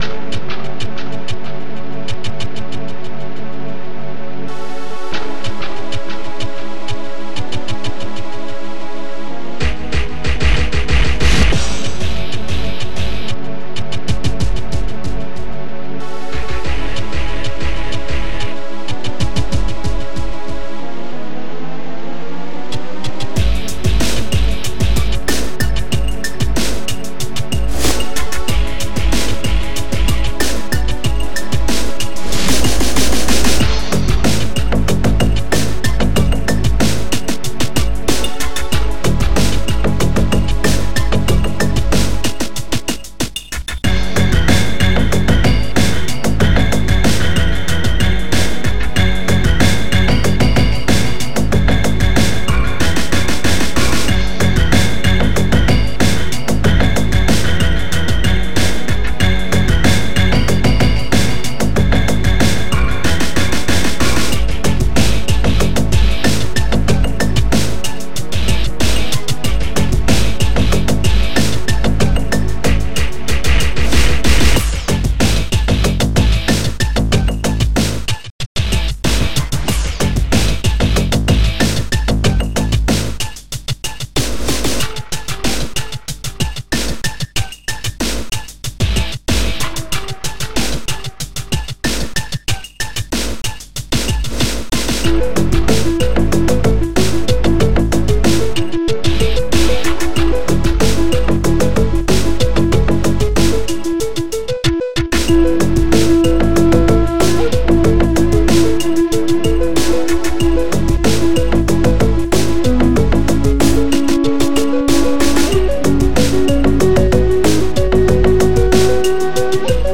Synth
Oktalyzer Module